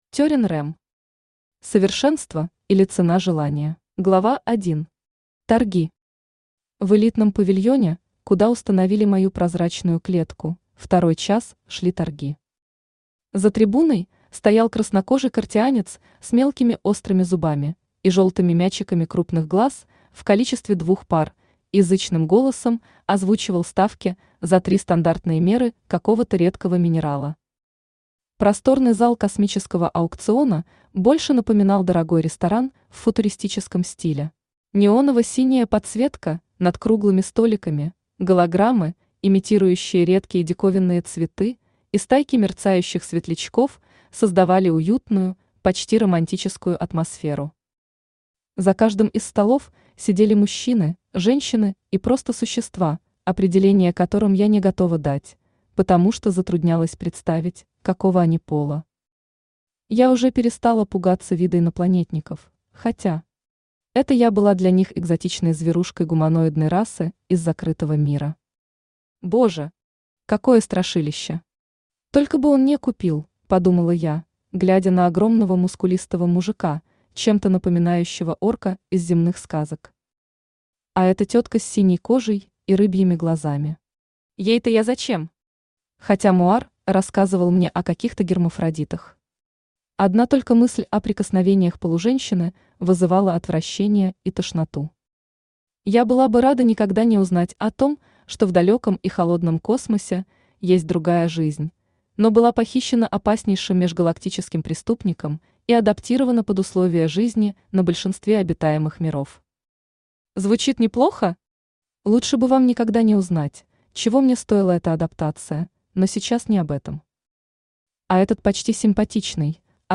Аудиокнига Совершенство, или Цена желания | Библиотека аудиокниг
Aудиокнига Совершенство, или Цена желания Автор Терин Рем Читает аудиокнигу Авточтец ЛитРес.